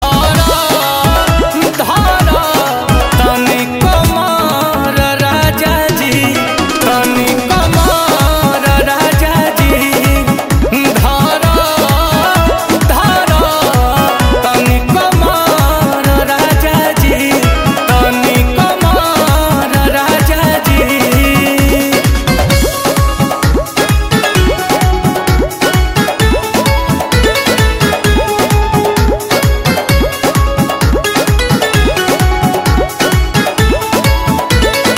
Enjoy this trending Bhojpuri hit tone for your phone.